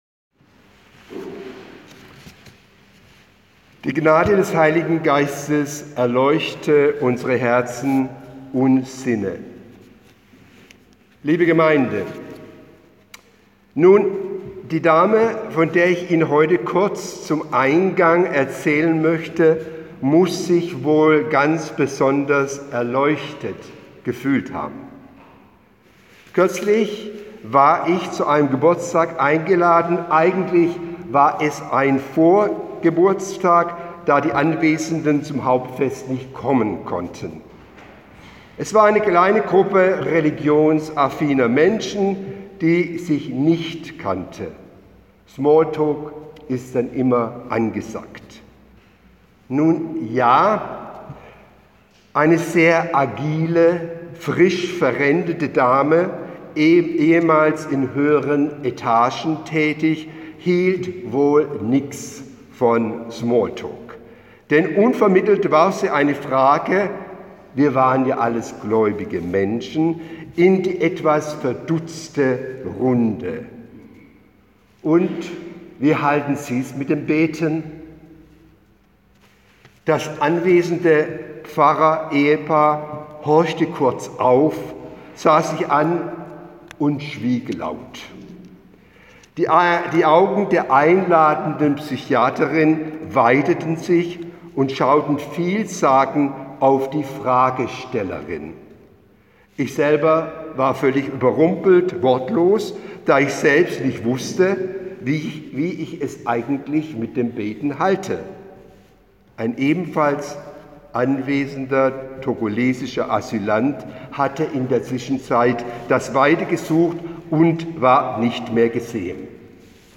Predigt zu Rogate